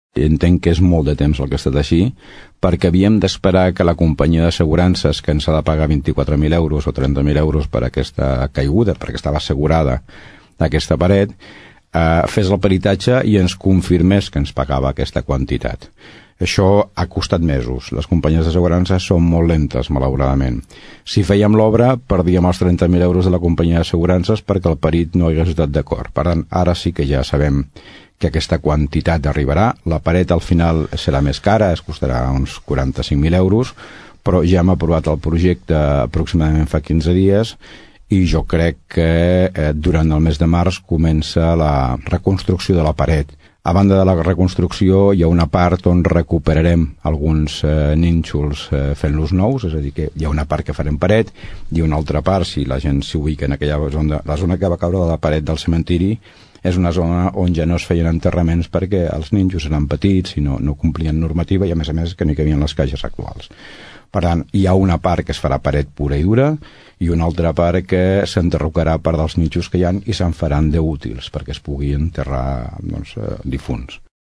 Joan Carles Garcia explica que la tardança és a causa de la companyia asseguradora i que el projecte d’obres ja està aprovat.
alcalde-mur-cementiri-vell.mp3